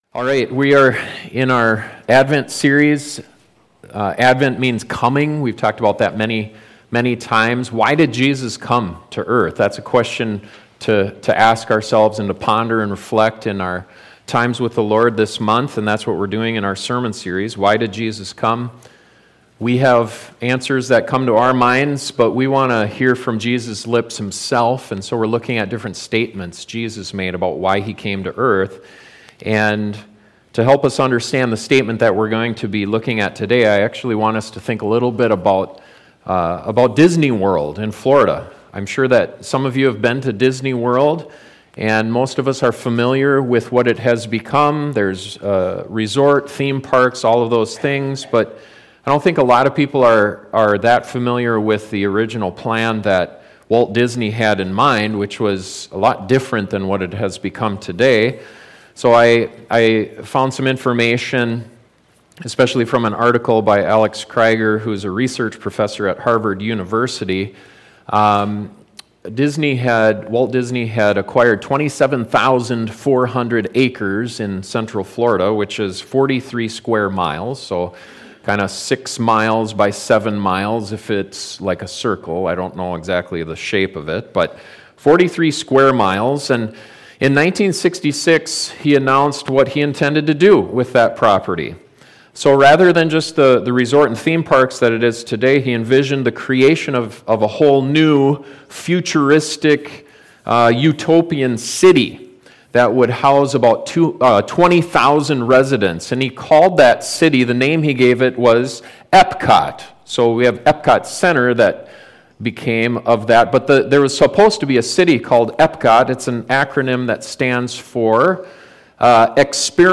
Why did Jesus come to earth? This sermon looks at an answer he gave to the religious leaders of his day and it challenges us as Christians to consider whether we’re more like him or them.